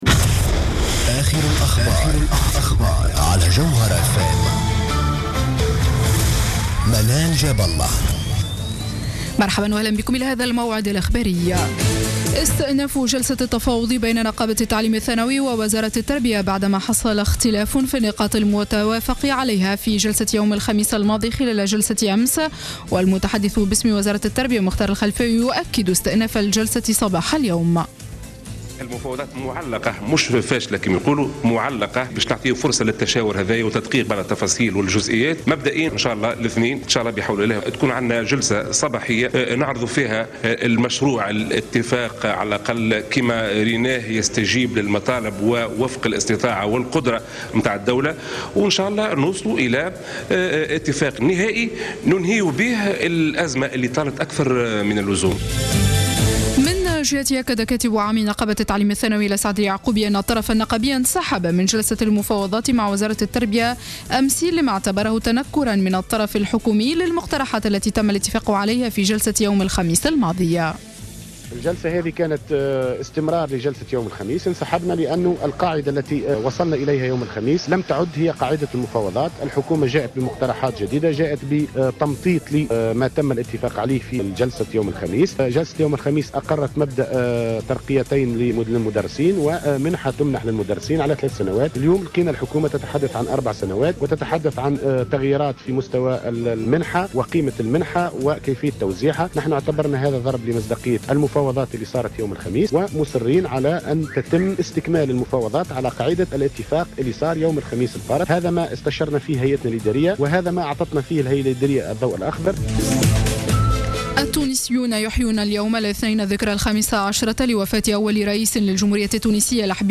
نشرة أخبار منتصف الليل ليوم الاثنين 6 أفريل 2015